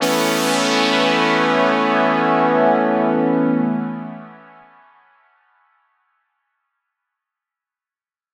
Chords_Amaj_01.wav